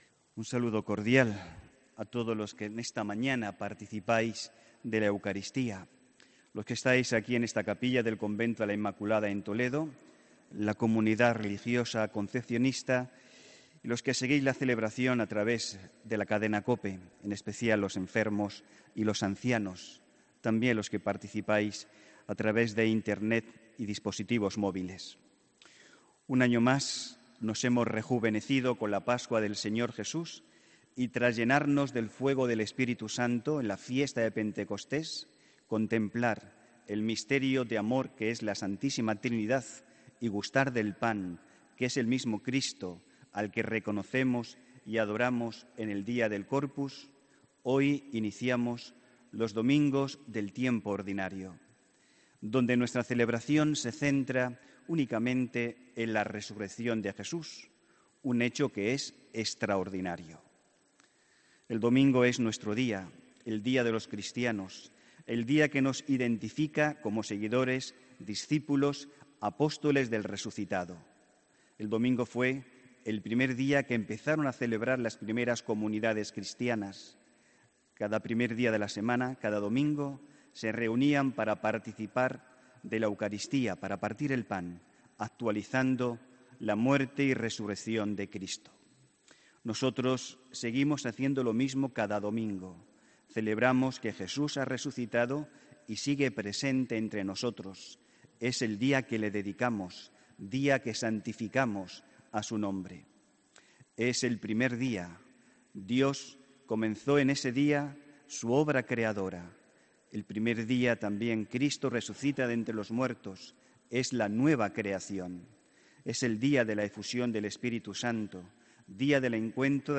HOMILÍA 10 JUNIO 2018